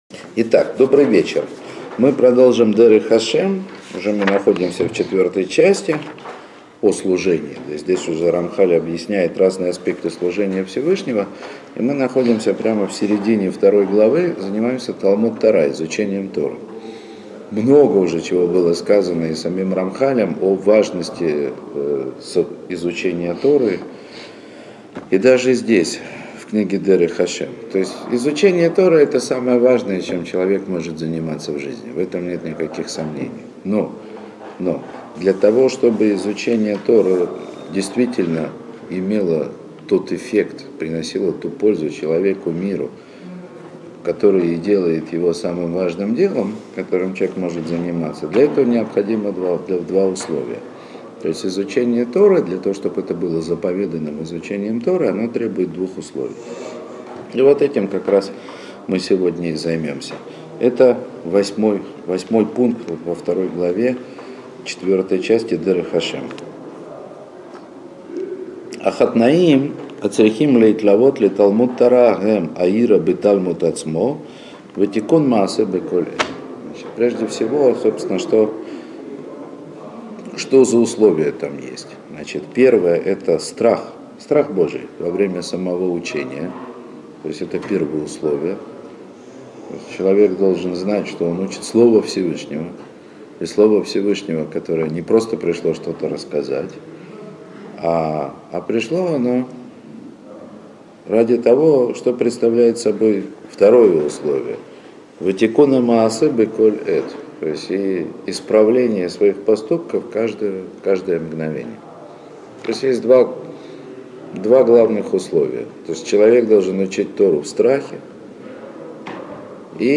Уроки по книге Рамхаля Дерех Ашем.